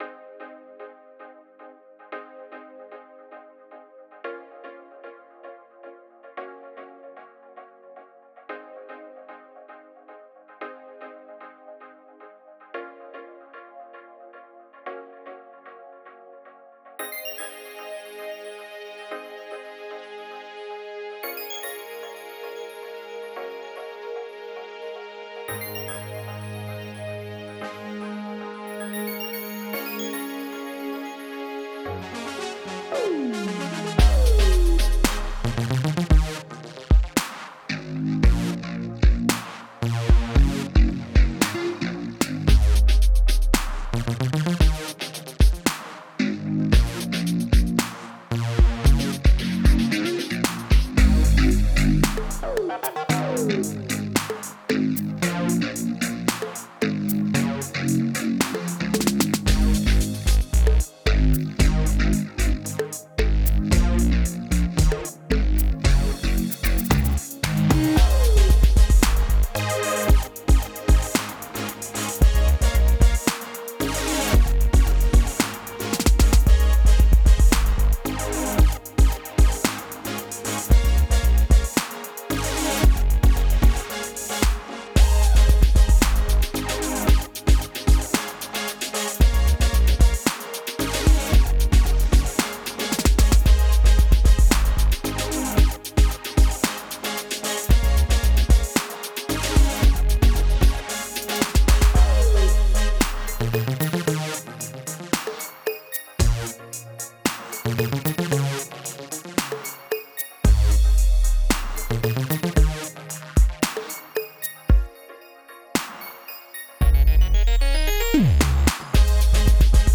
Hallo, der erste Versuch ein Lied zu bauen.